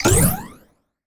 collect_item_22.wav